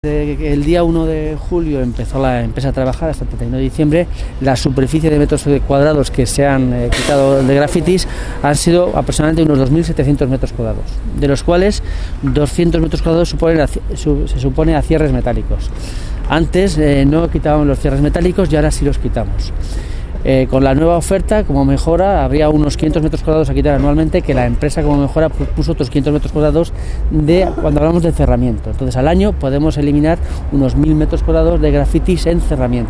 Declaraciones de Francisco Úbeda: Descargar Declaraciones de Francisco Úbeda, concejal de Medio Ambiente: Descargar Declaraciones de Francisco Úbeda, concejal de Medio Ambiente del Ayuntamiento: Descargar